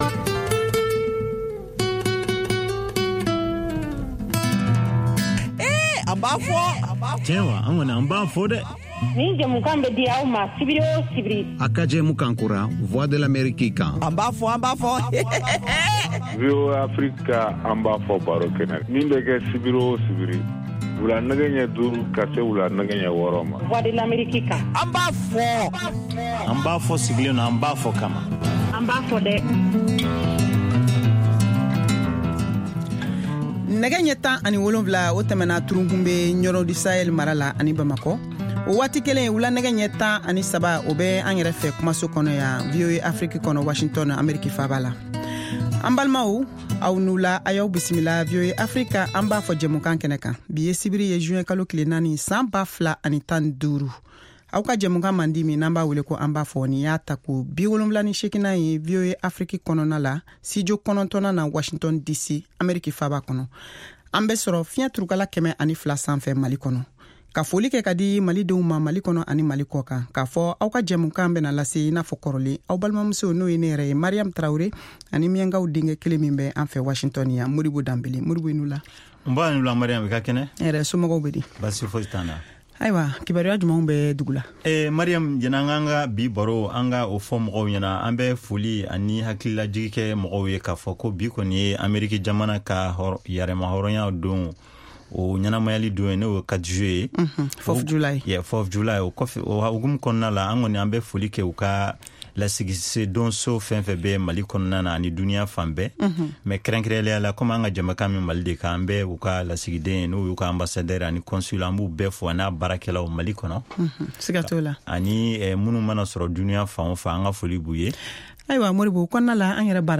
Bambara Call-in Show